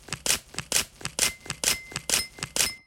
camera click paparazzi picture sound effect free sound royalty free Sound Effects